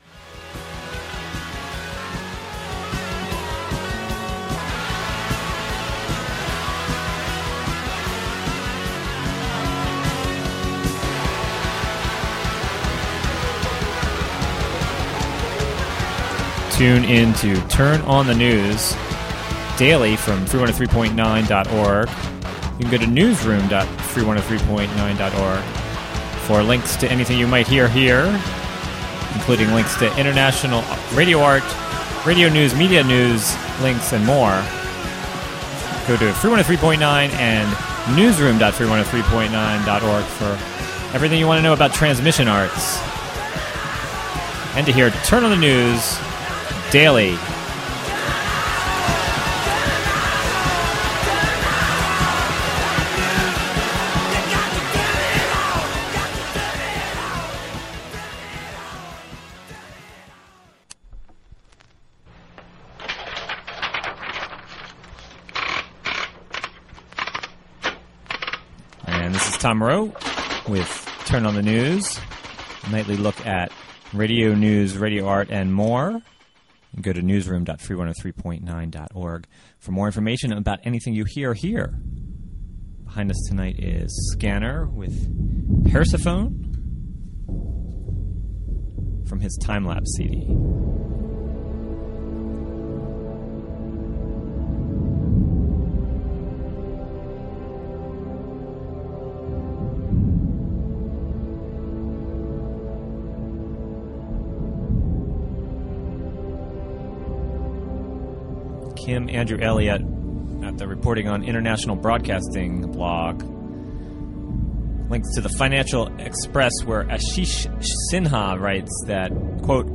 Turn On the News is a daily radio news program fea...